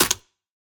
Minecraft Version Minecraft Version 1.21.5 Latest Release | Latest Snapshot 1.21.5 / assets / minecraft / sounds / block / mangrove_roots / step1.ogg Compare With Compare With Latest Release | Latest Snapshot
step1.ogg